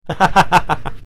Man Laughing 04
Man_laughing_04.mp3